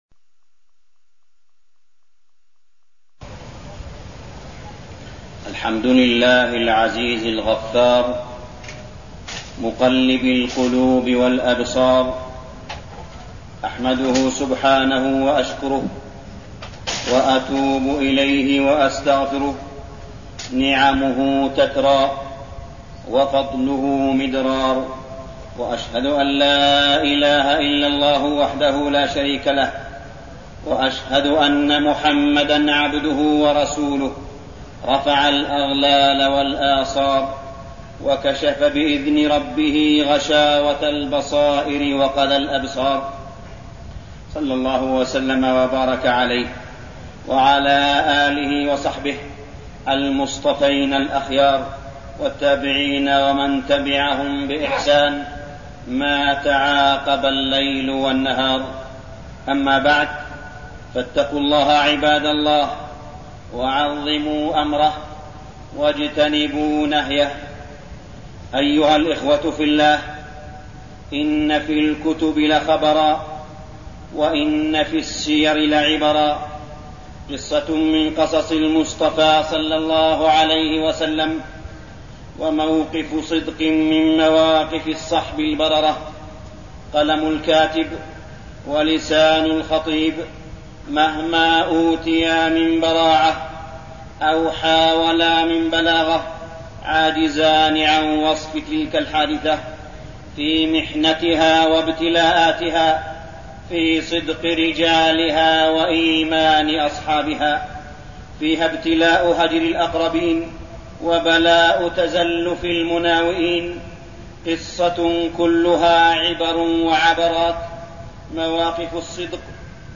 تاريخ النشر ١٧ جمادى الأولى ١٤١٠ هـ المكان: المسجد الحرام الشيخ: معالي الشيخ أ.د. صالح بن عبدالله بن حميد معالي الشيخ أ.د. صالح بن عبدالله بن حميد قصة الثلاثة الذين خلفوا في غزوة تبوك